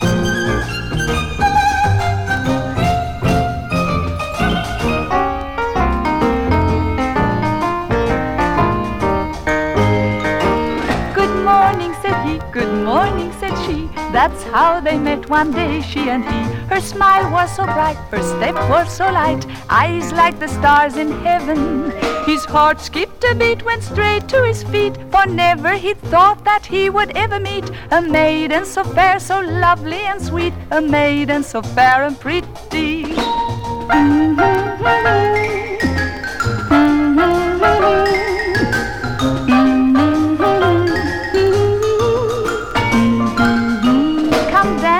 なんと、25回の多重録音で創出した凄み溢れる世界。
Jazz, Pop, Experimental　USA　12inchレコード　33rpm　Stereo